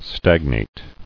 [stag·nate]